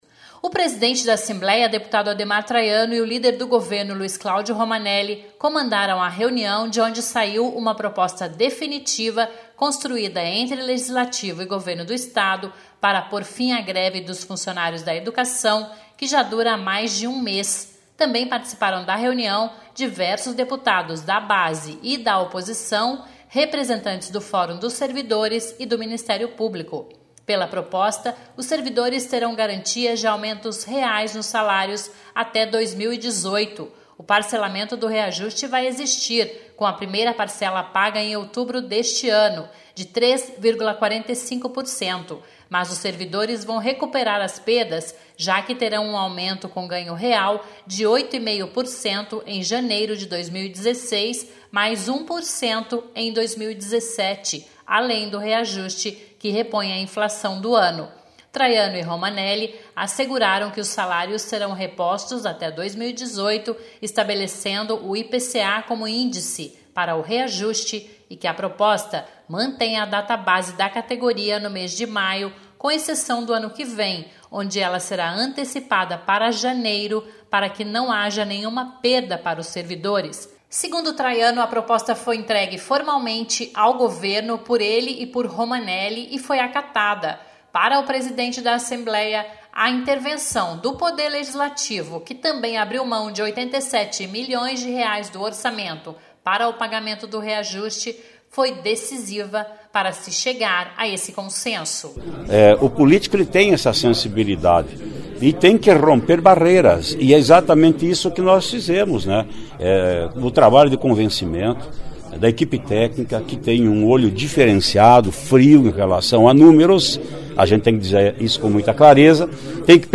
(sonora)